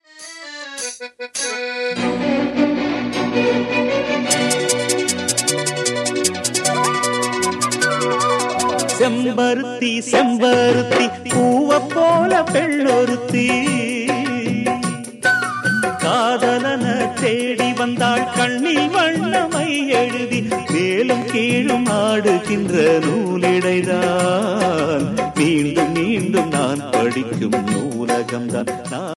melody ringtone